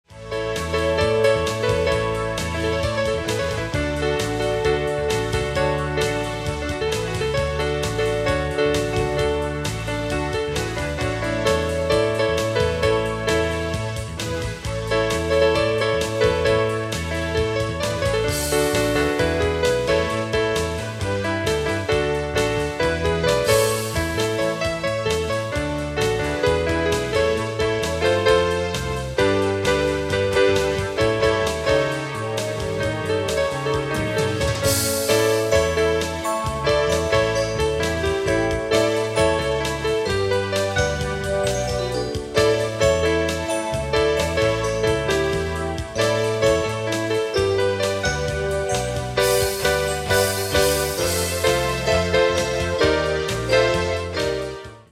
80's Synth Rock